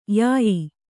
♪ yāyi